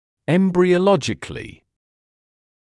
[ˌembrɪə»lɔʤɪklɪ][ˌэмбриэ’лодиджикли]эмбрионологически; на стадии эмбриона